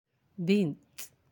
(bint)
bint.aac